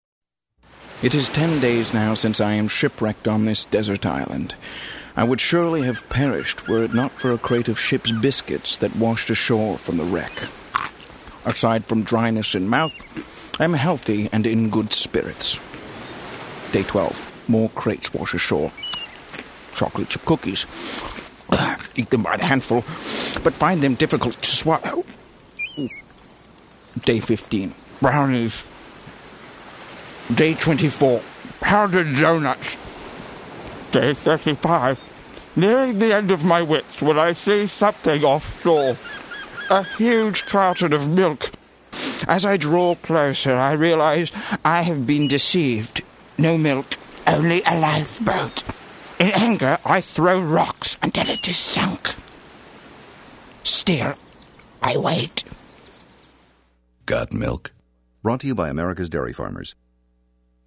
GS&P turned to radio for its unique ability to create great theater-of-mind (and thirst-for-milk), as here: